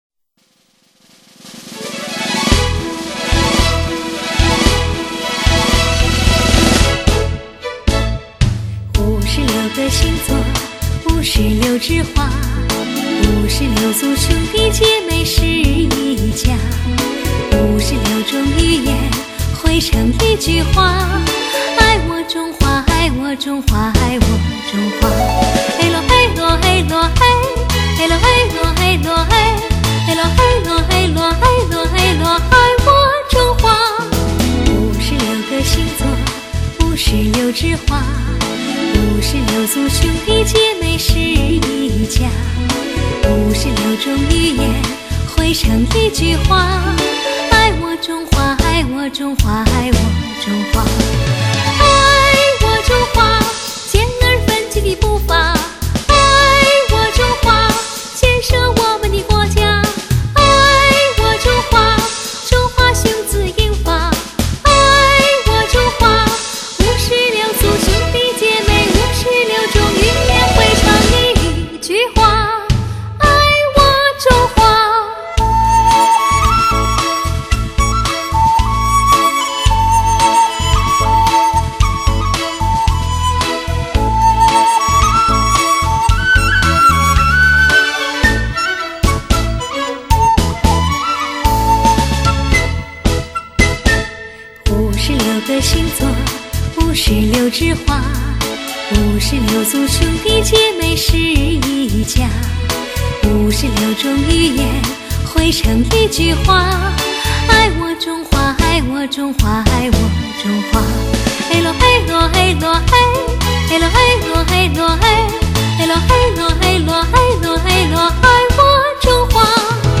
金曲再现  百万畅销  歌声悠扬  婉转动听  歌唱改革开放30年  谱写光辉历程